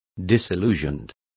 Also find out how desenganado is pronounced correctly.